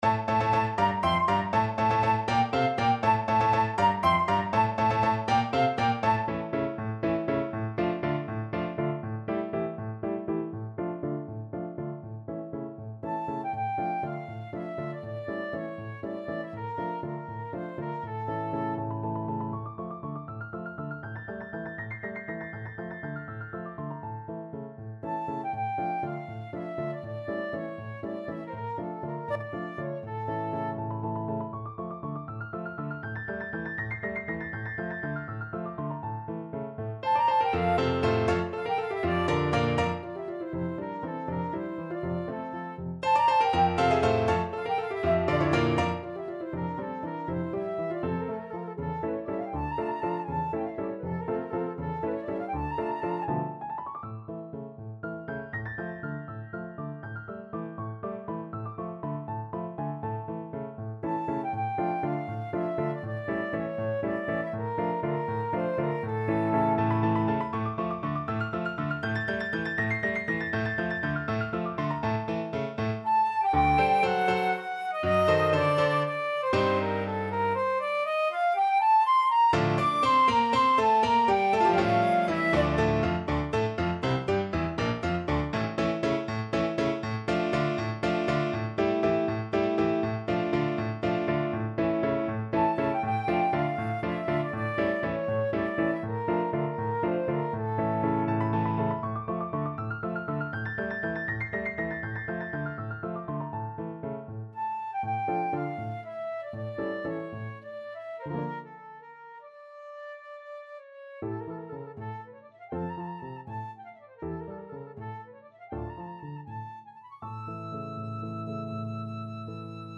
Free Sheet music for Flute
Flute
3/8 (View more 3/8 Music)
Allegro vivo (.=80) (View more music marked Allegro)
D minor (Sounding Pitch) (View more D minor Music for Flute )
Classical (View more Classical Flute Music)